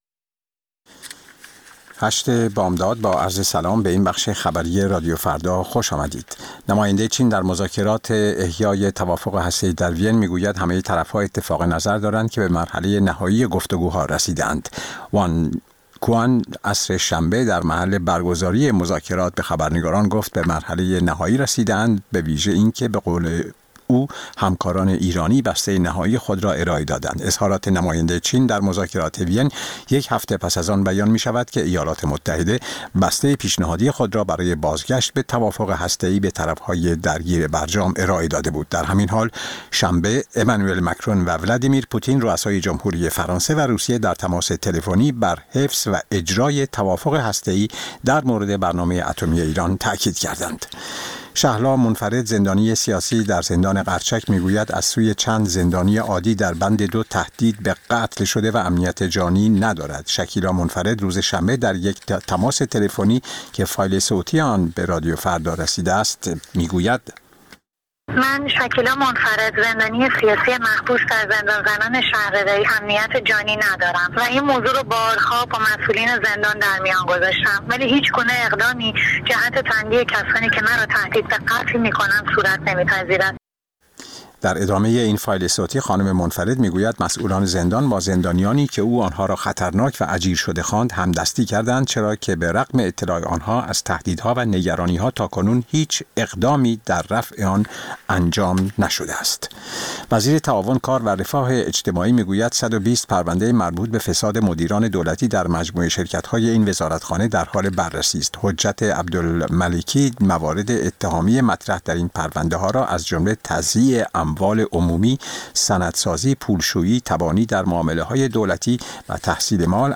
سرخط خبرها ۸:۰۰
پخش زنده - پخش رادیویی